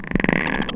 rocket_idle_chirp2.wav